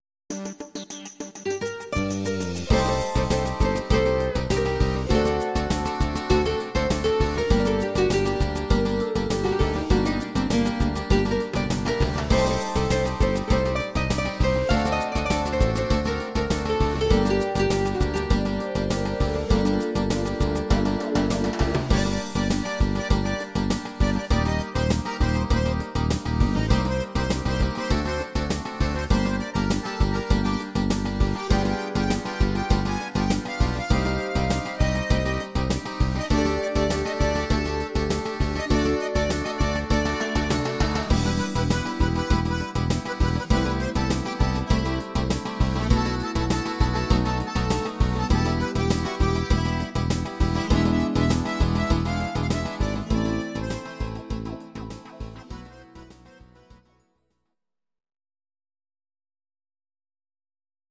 Audio Recordings based on Midi-files
Pop, Ital/French/Span, Duets